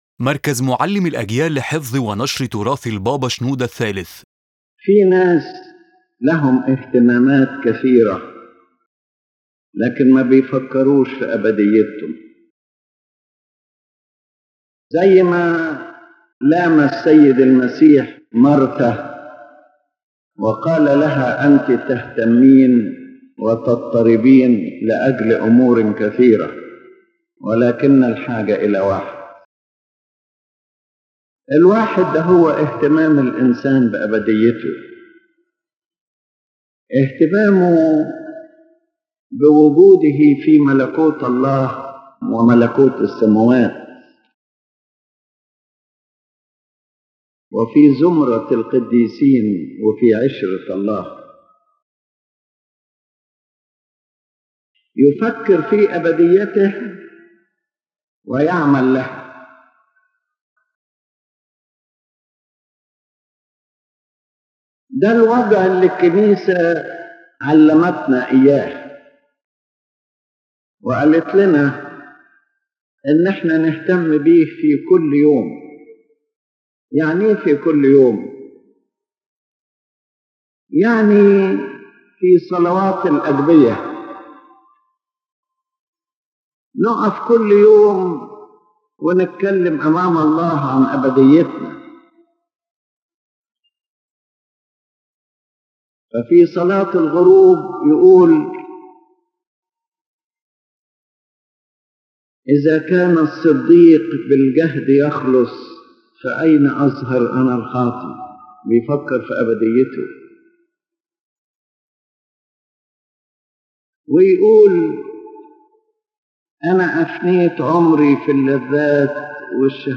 In this lecture, His Holiness Pope Shenouda III explains that the most important concern in a person’s life should be his eternity, for it is the final destiny that gives meaning to life on earth.